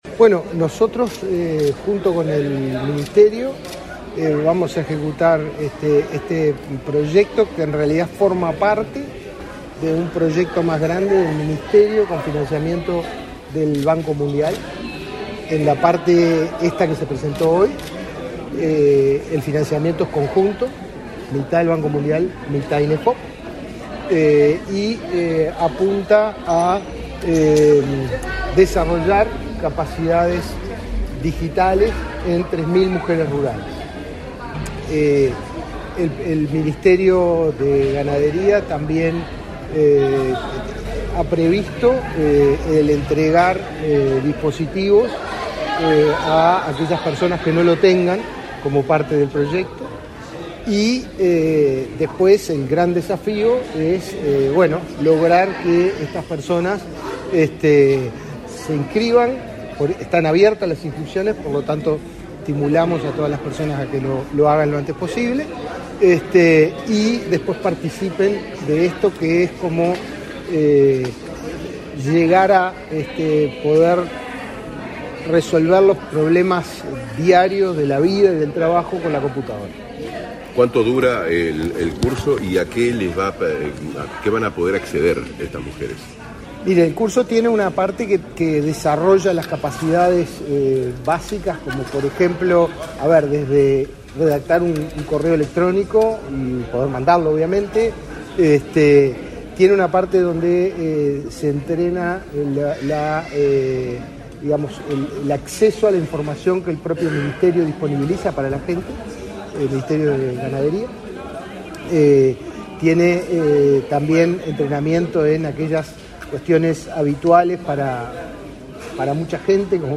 Declaraciones a la prensa del director general del Inefop, Pablo Darscht
Declaraciones a la prensa del director general del Inefop, Pablo Darscht 06/07/2023 Compartir Facebook X Copiar enlace WhatsApp LinkedIn El Ministerio de Ganadería, Agricultura y Pesca (MGAP) y el Instituto Nacional de Empleo y Formación Profesional (Inefop) firmaron, este 6 de julio, un convenio para capacitar a unas 3.000 mujeres que trabajan en el agro y la pesca. Tras el evento, el director del Inefop realizó declaraciones a la prensa.